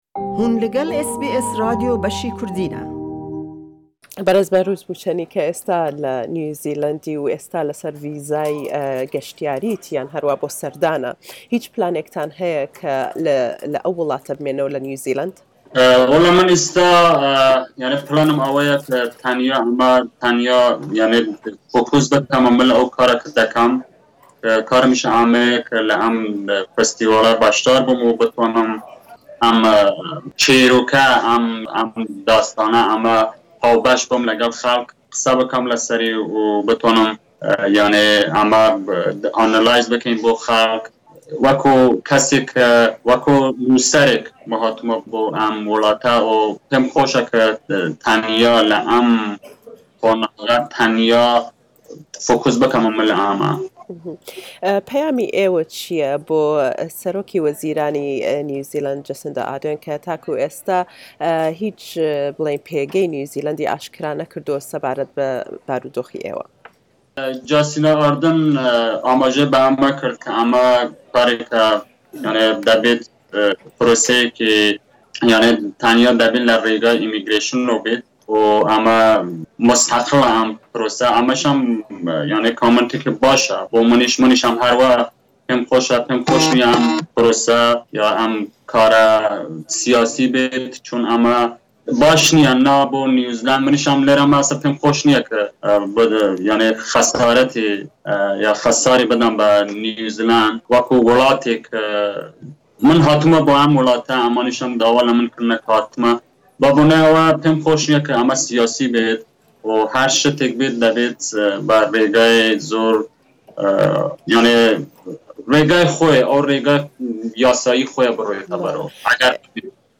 Behrooz Boochani speaking exclusively with SBS Kurdish Source: SBS Kurdish